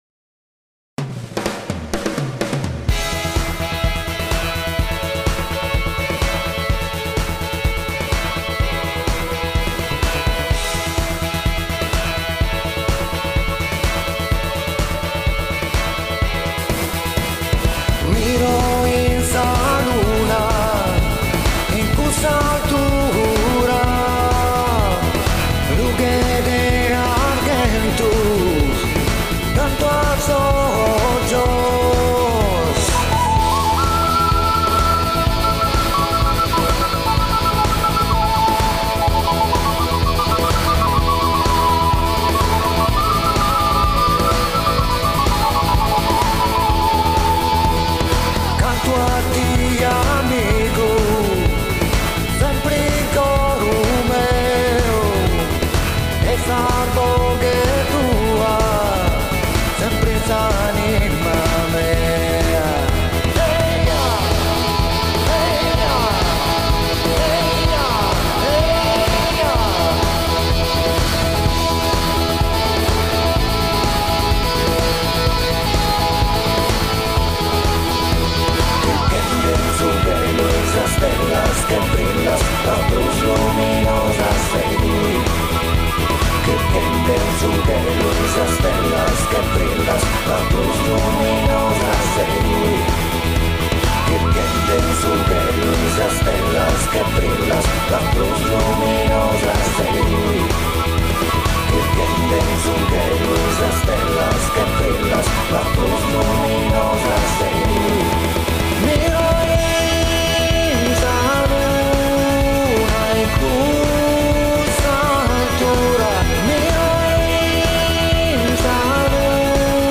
dance/electronic
Prog rock